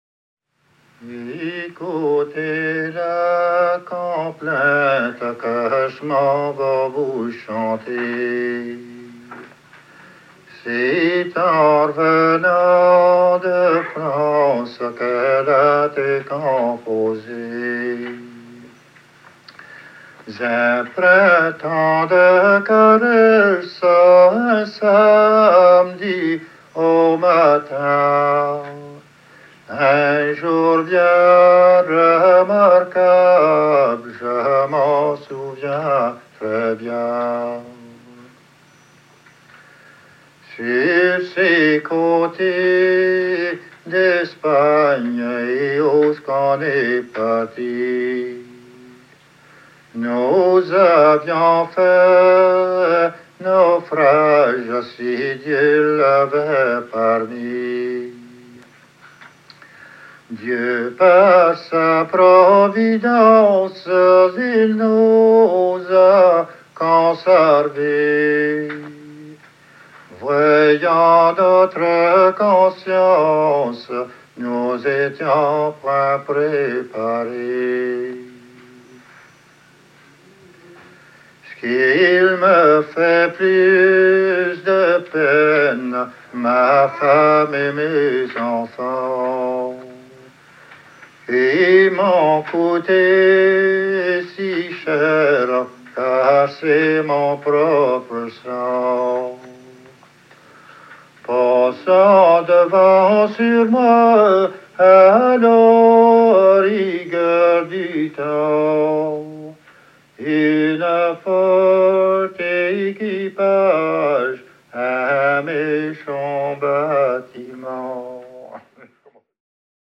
Genre strophique
Catégorie Pièce musicale éditée